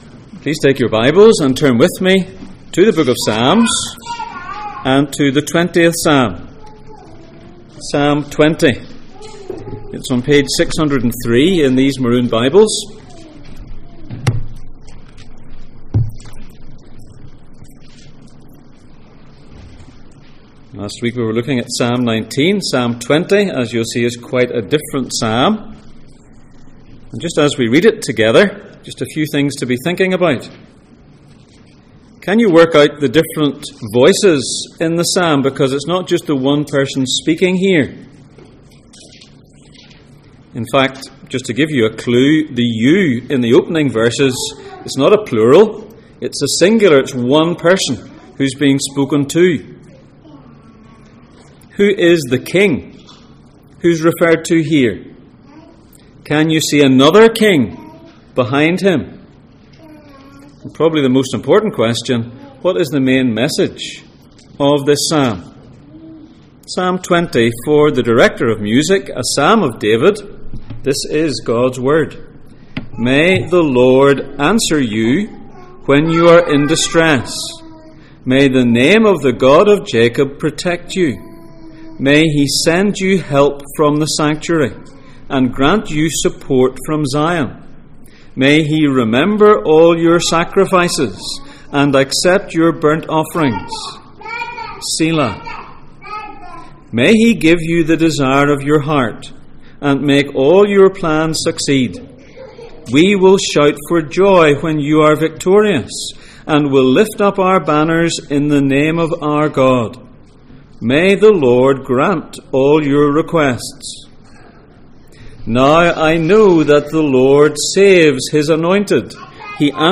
Psalms Passage: Psalm 20:1-9, Romans 8:30 Service Type: Sunday Morning %todo_render% « Whats it all about?